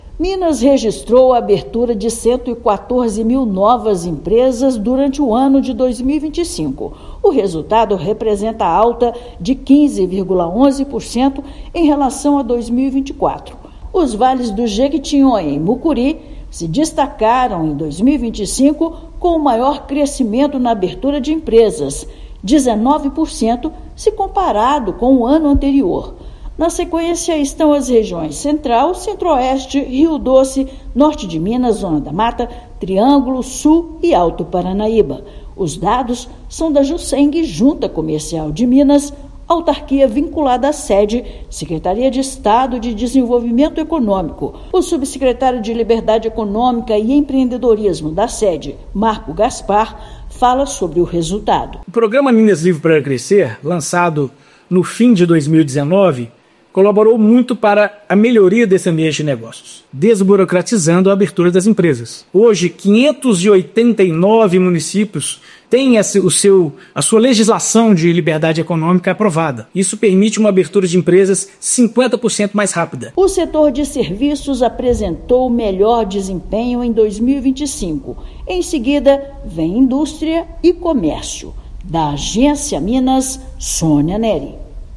[RÁDIO] Minas Gerais teve mais de 114 mil novas empresas abertas em 2025